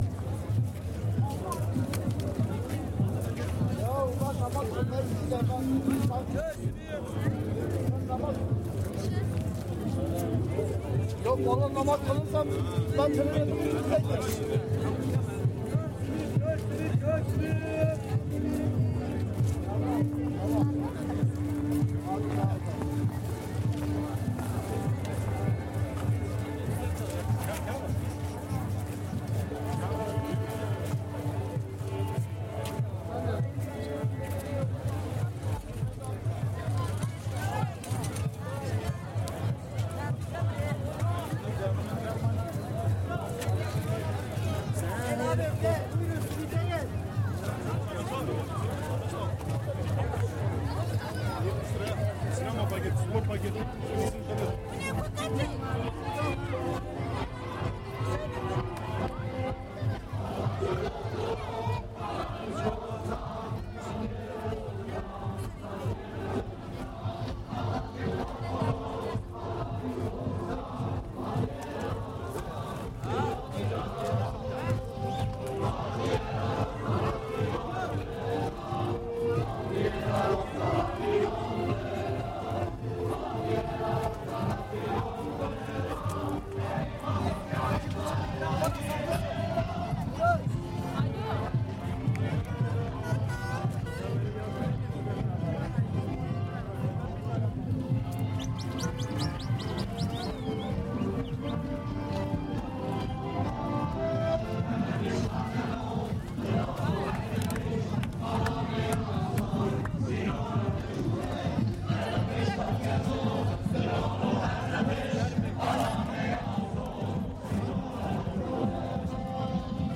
Kurdish celebrations reimagined